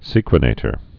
(sēkwə-nātər)